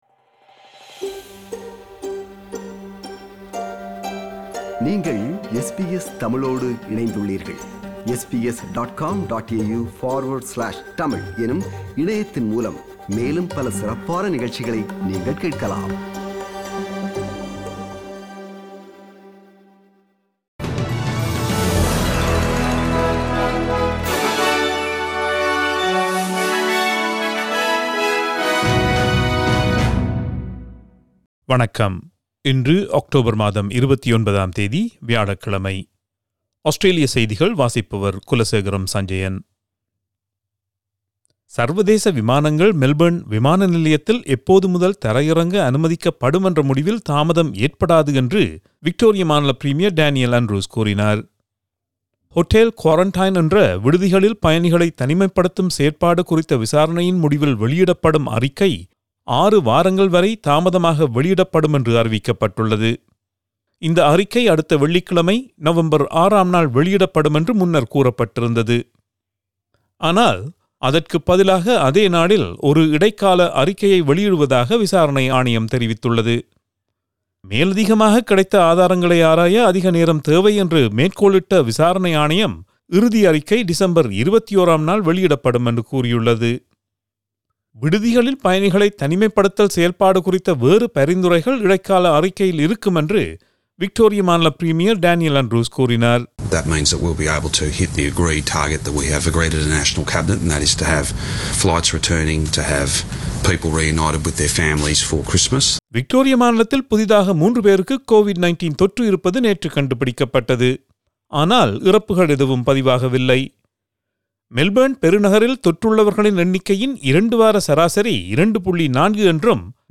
Australian news bulletin for Thursday 29 October 2020.